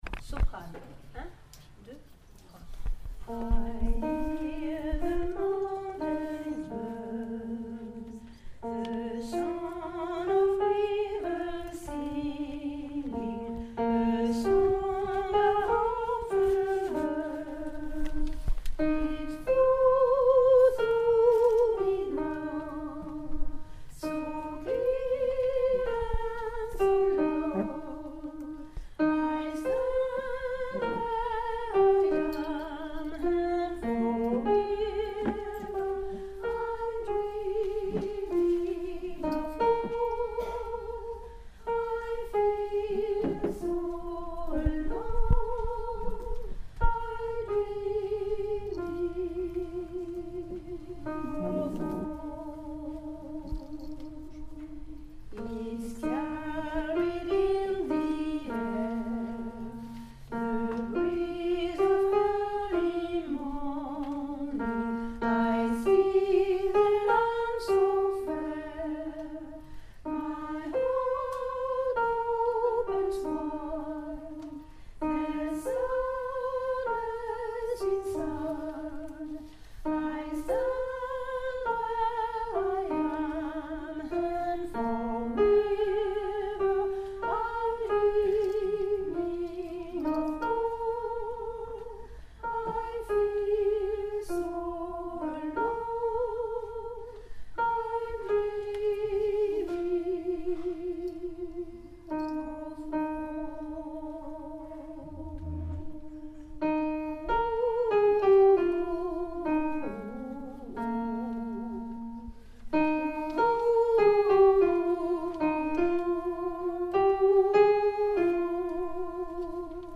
Enregistrement SOPRANO